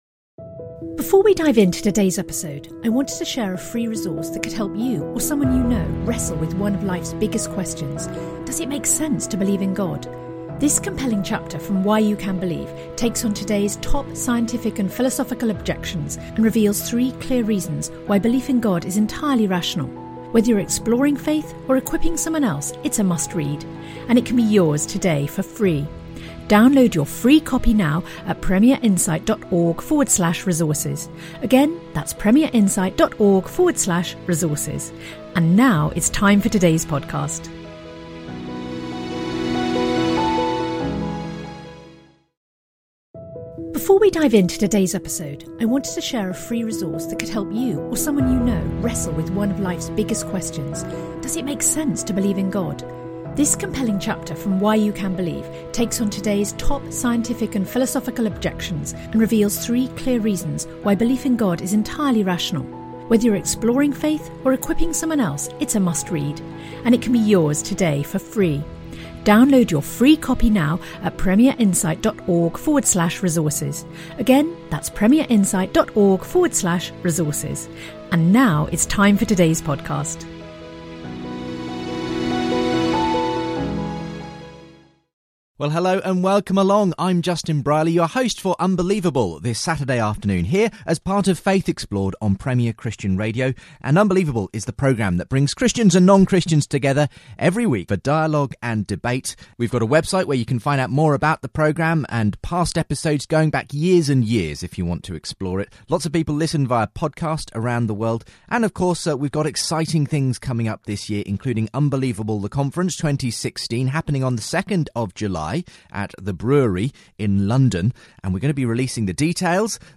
faith debates